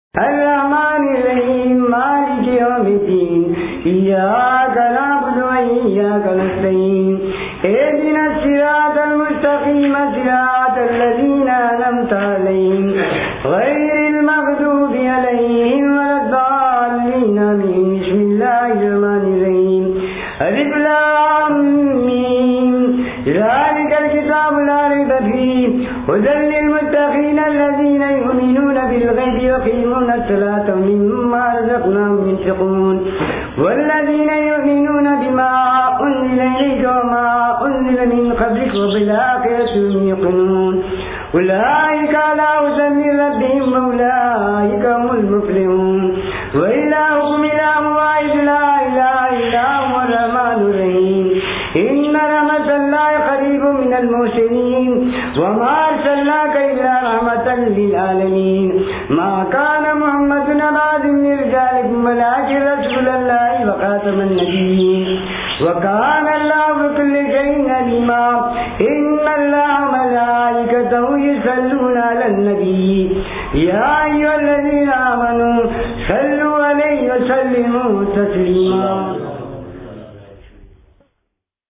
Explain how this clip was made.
I made a number of field recordings of Qawwali performances in Khuldabad in July, 2004.